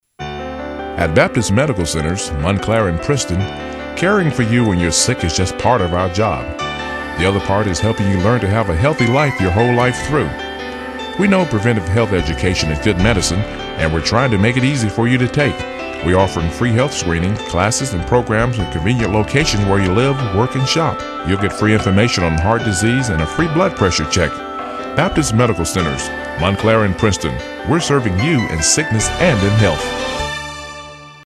Baptist Medical Radio/TV ad Conversational/informative
African American
Middle Aged
Voice is a deeper smooth tone.